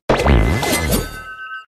ceruledge_ambient.ogg